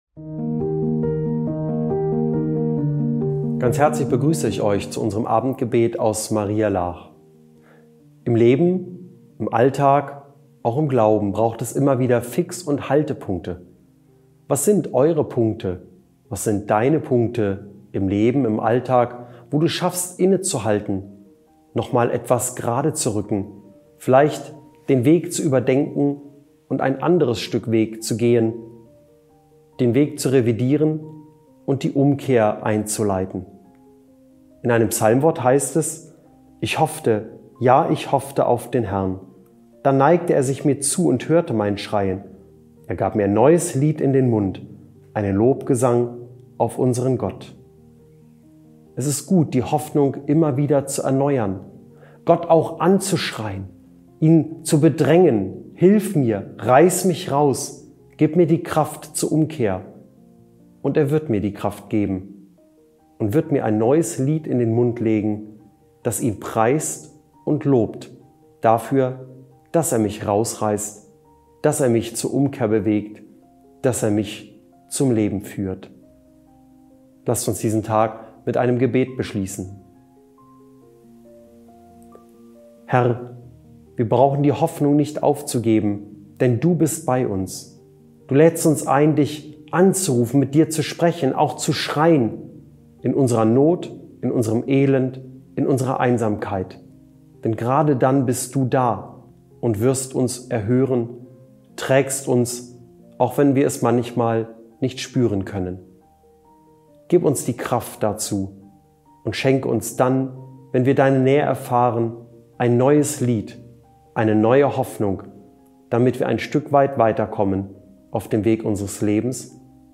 Abendgebet